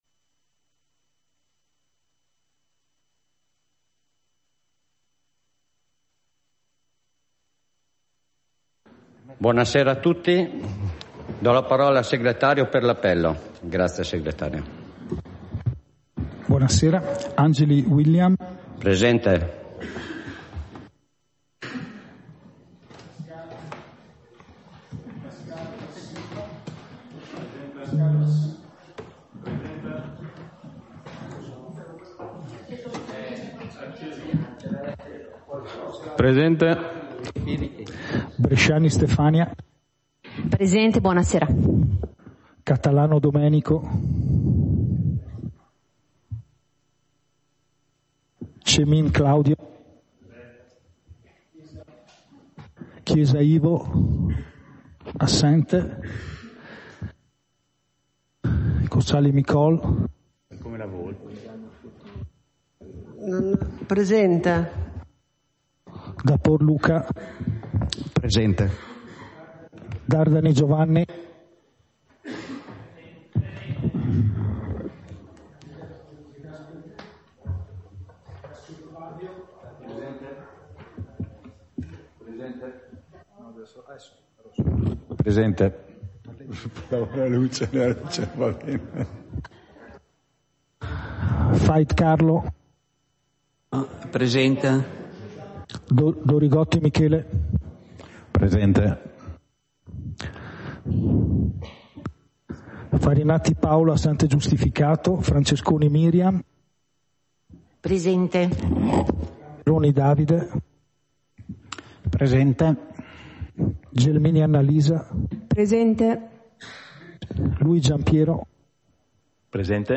Seduta del consiglio comunale - 2 luglio 2024